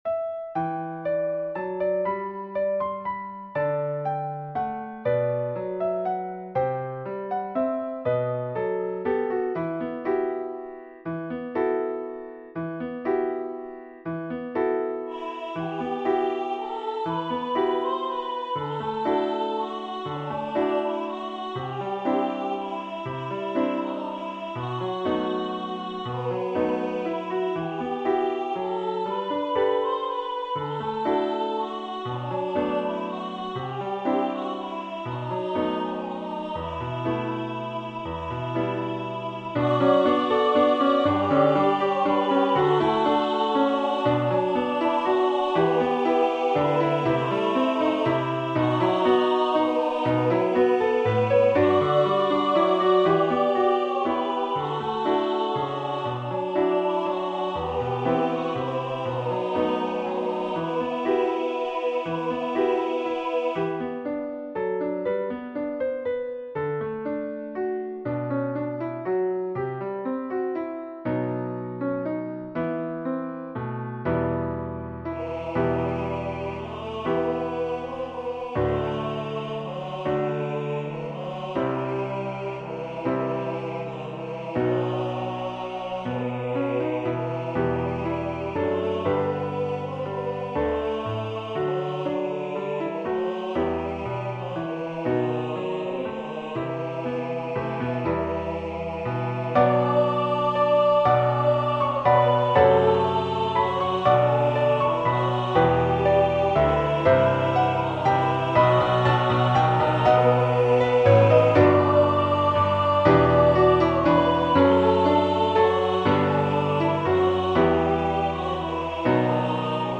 SATB with piano accompaniment.
Voicing/Instrumentation: SATB